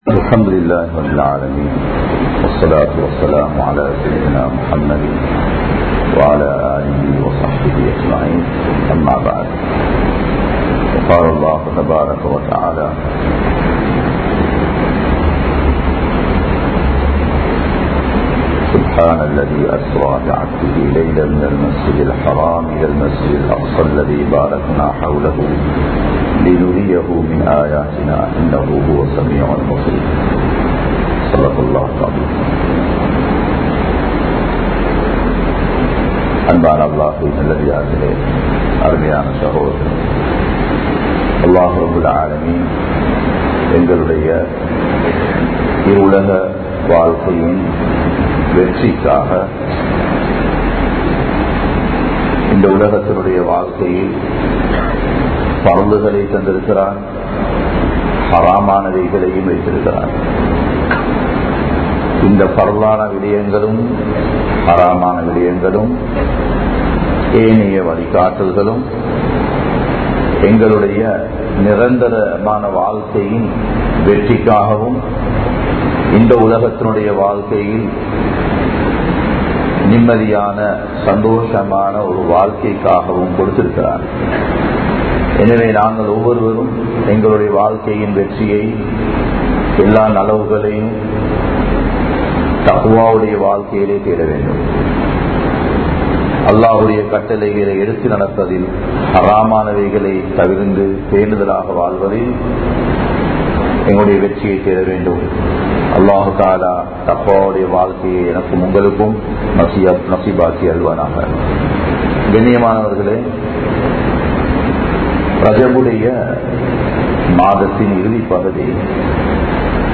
Mihraj Koorum Paadam (மிஃராஜ் கூறும் பாடம்) | Audio Bayans | All Ceylon Muslim Youth Community | Addalaichenai
Gothatuwa, Jumua Masjidh